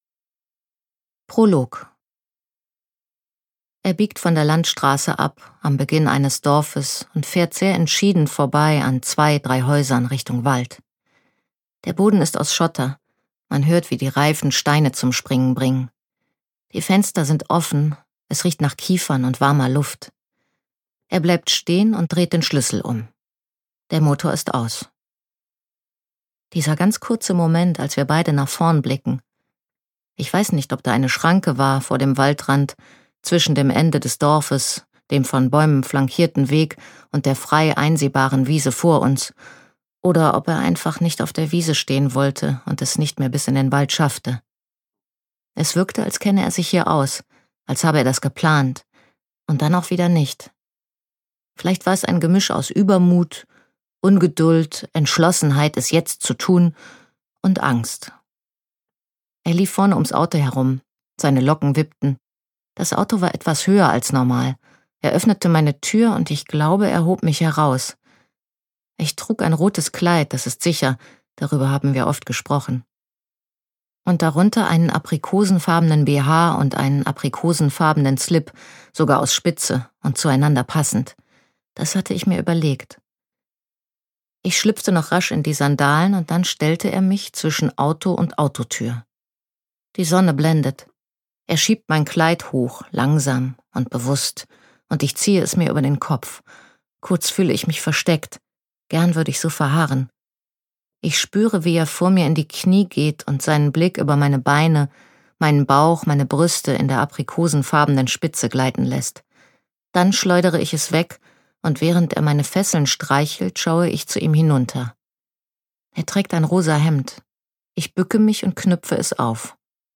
Gekürzt Autorisierte, d.h. von Autor:innen und / oder Verlagen freigegebene, bearbeitete Fassung.
Der Sex meines Lebens Gelesen von: Vera Teltz
Hörbuchcover von Der Sex meines Lebens: Kein Liebesroman
Vera TeltzSprecherin